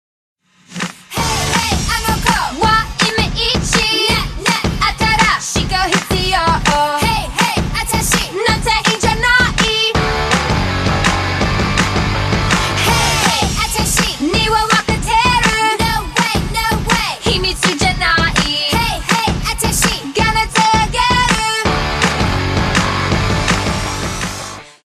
Traditional Japanese